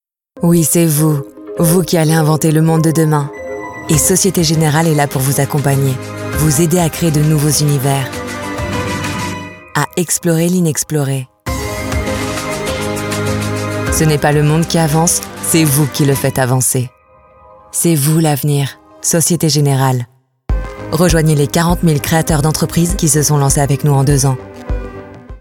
Naturelle, Distinctive, Polyvalente, Fiable, Chaude
Commercial